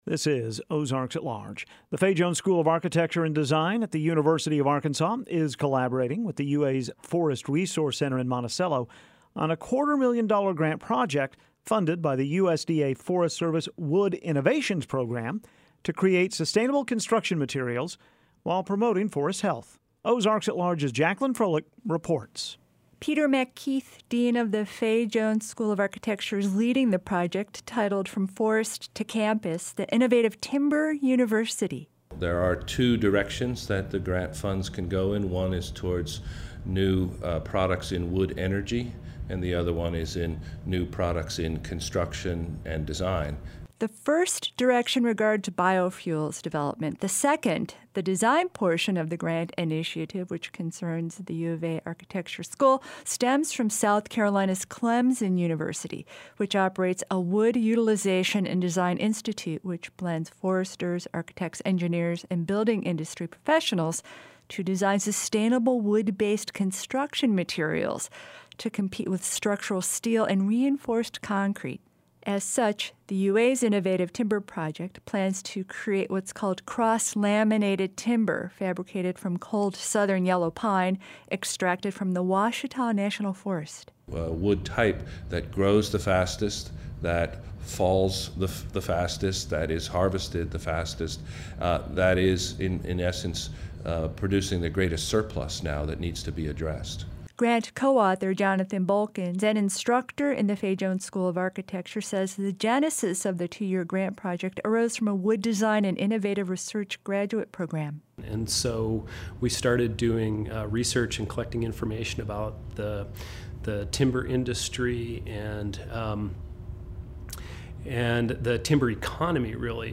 March 22, 2017  Interview on NPR